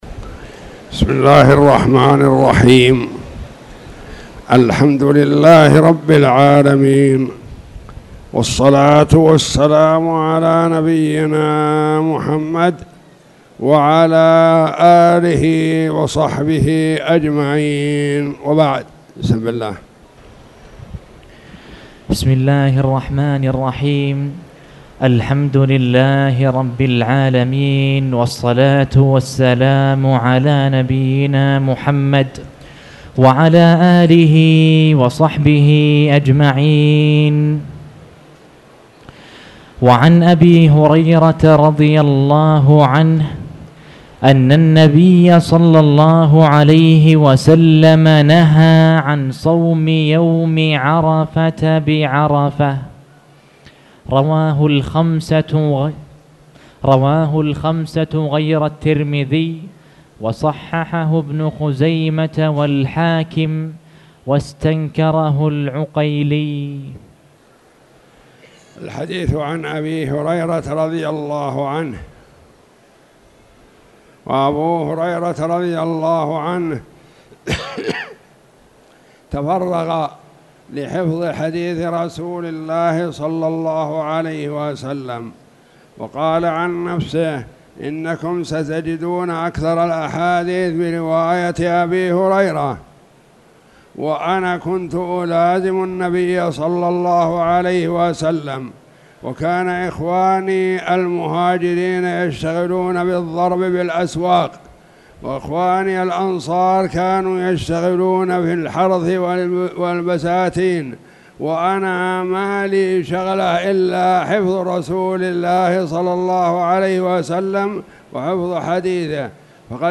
تاريخ النشر ٢٦ شوال ١٤٣٧ هـ المكان: المسجد الحرام الشيخ